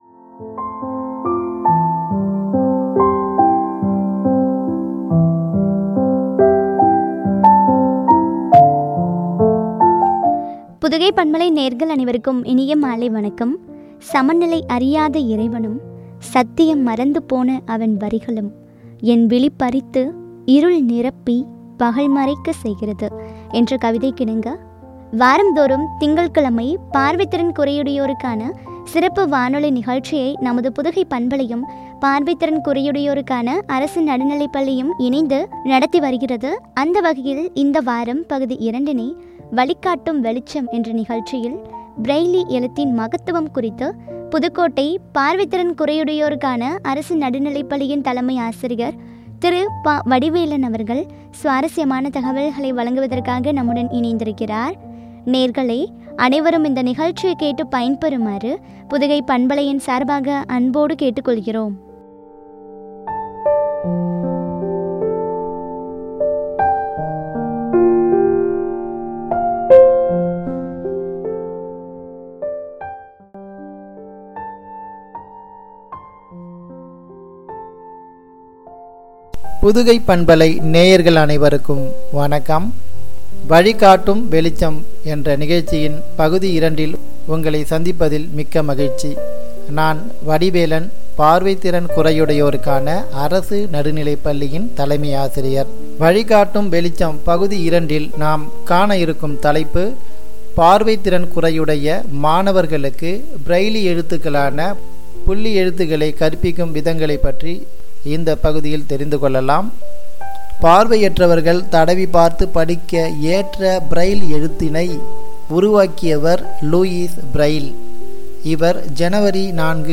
பார்வை திறன் குறையுடையோருக்கான சிறப்பு வானொலி நிகழ்ச்சி